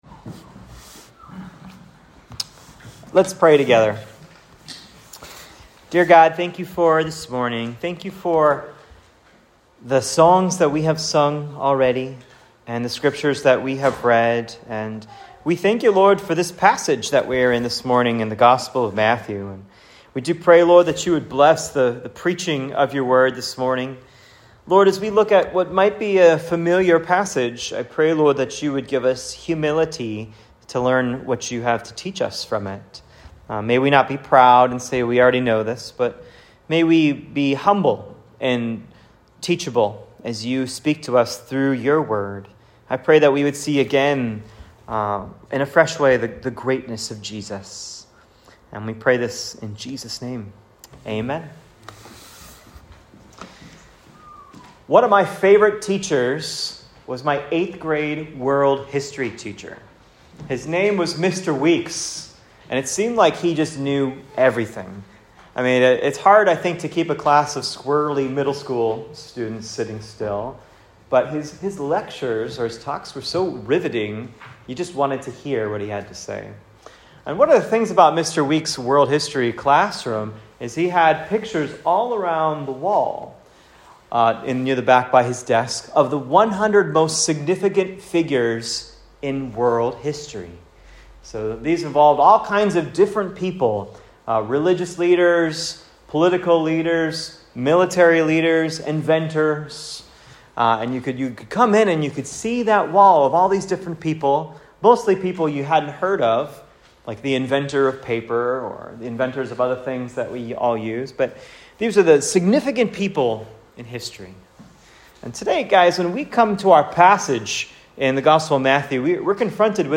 Matt 1:18-25 Sermon “God With Us”